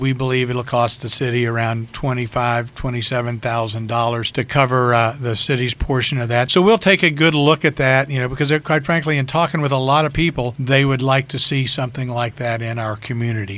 The City of Cumberland’s plans to build a sports complex took another step recently when it was revealed that the Appalachian Regional Commission has agreed to a grant to help pay for a feasibility study on the proposal. Mayor Ray Morriss said the A-R-C will pay for about 70% of the study…